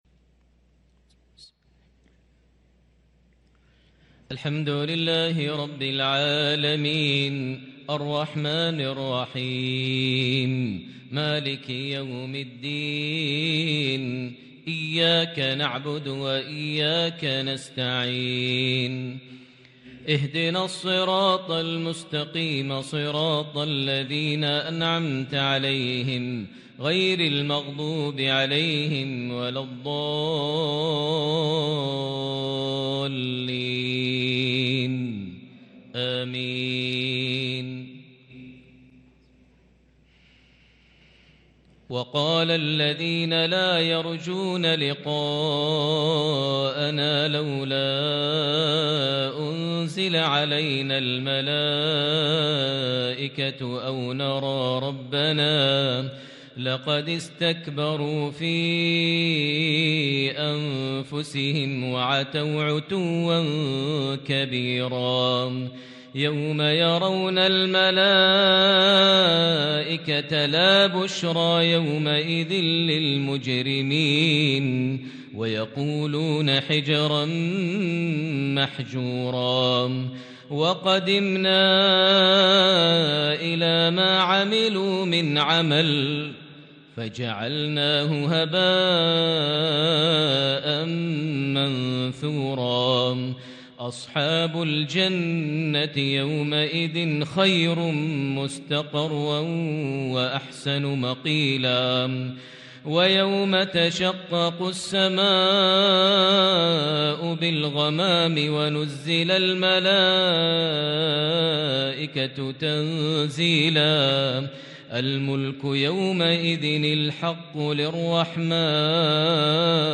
عشاء ٣-٧- ١٤٤٣هـ سورة الفرقان | Isha prayer from Surah al-Furqan 4-2-2022 > 1443 🕋 > الفروض - تلاوات الحرمين